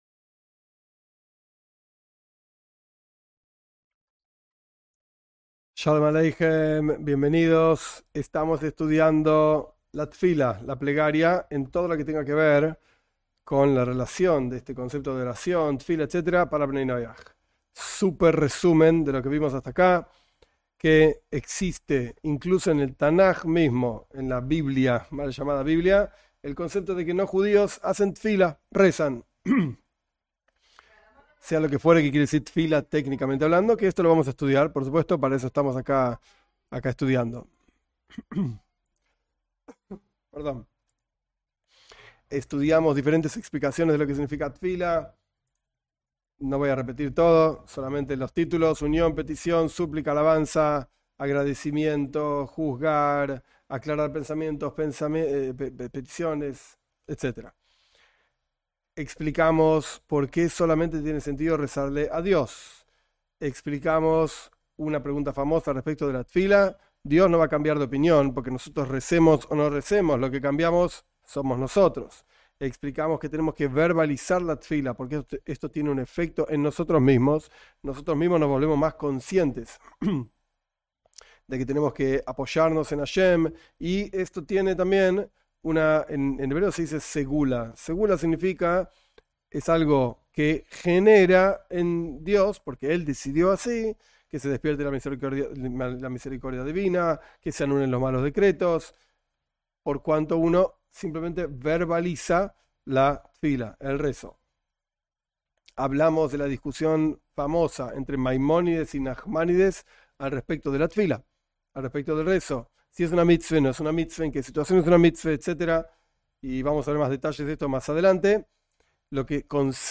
En estas clases se analiza con detenimiento la plegaria para no Judíos según el mensaje del judaísmo.